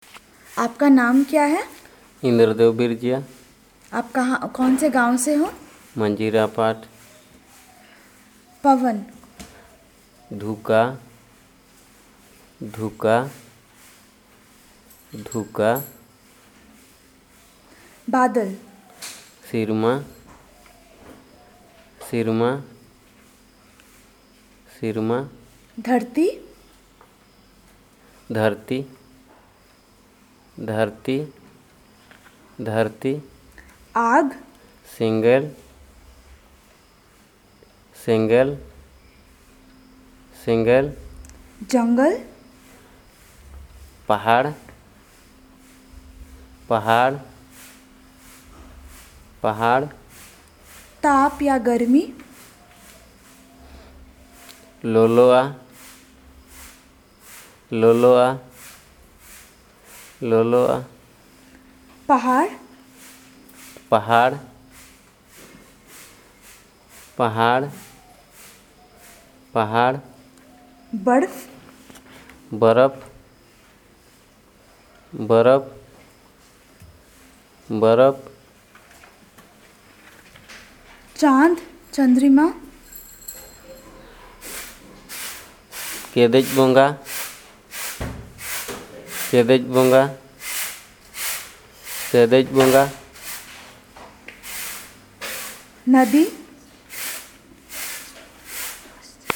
Elicitation of words about nature and related
NotesThis an elicitation of words about nature and related such as air, fire, water, earth, ice, moon, and so on. Hindi is the language of input from the researcher's side, which the informant then translates to the language of interest